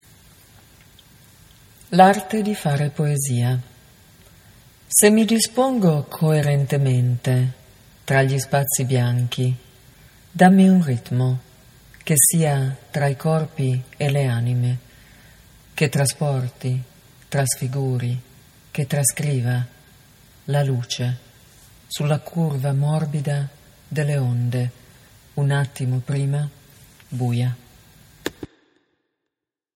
To hear the poet’s reading of this poem, click on the player below: